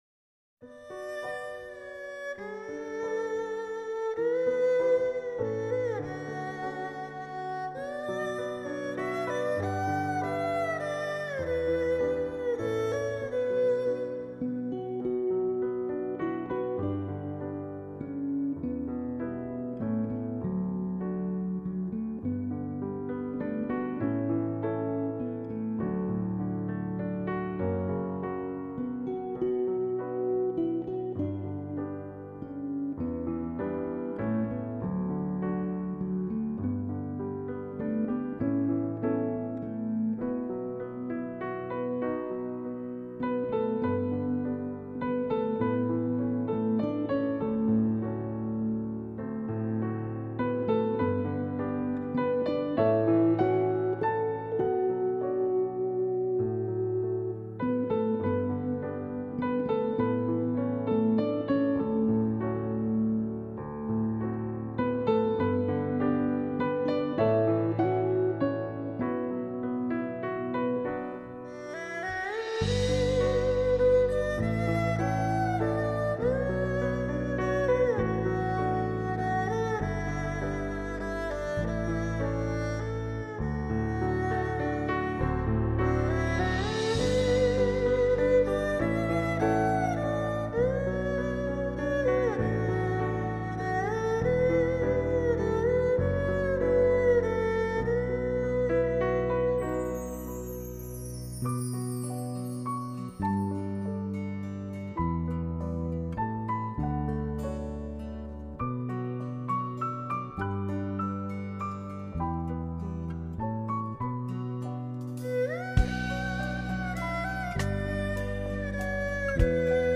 治愈系钢琴音画
钢琴演奏
二胡演奏